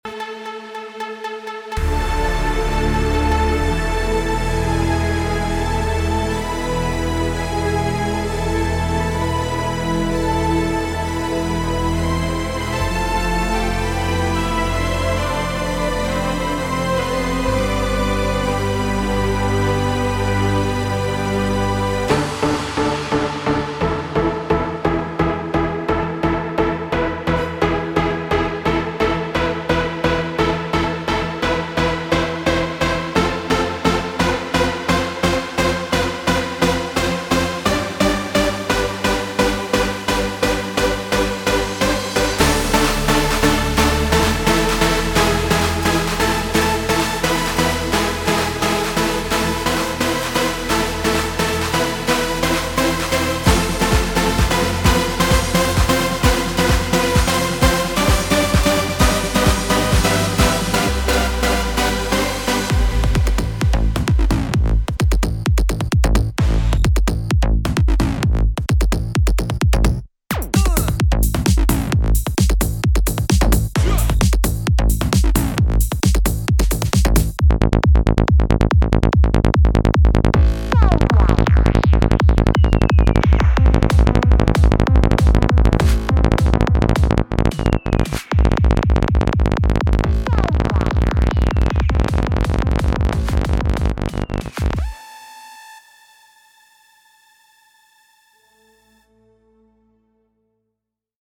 מקצבים שבניתי על קורג.
יפה מאוד אבל המנגינה לא ברורה…